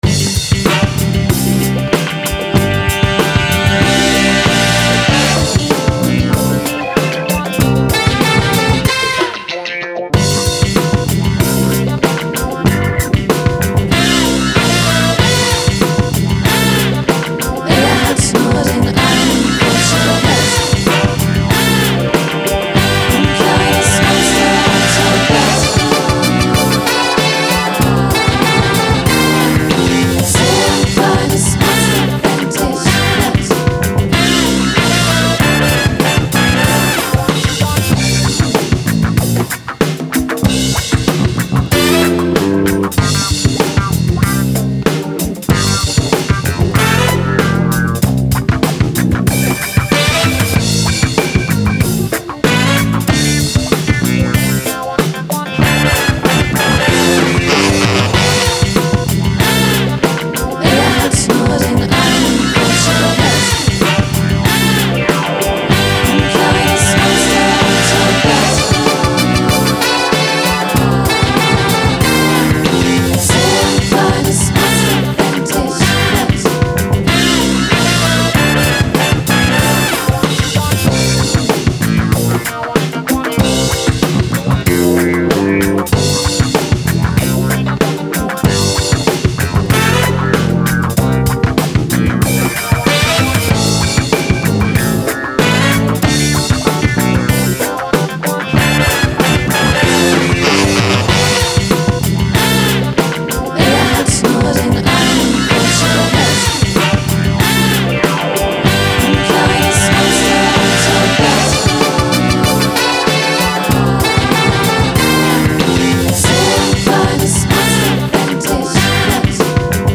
Das Notenblatt mit Akkorden und Akkordbildern zum Singen und Spielen von „Monster unter’m Bett“ findet ihr hier; ein Halbplayback zum Üben (oder als Ersatz für die Band) liegt an dieser Stelle.
MonsterUntermBettPlayback.mp3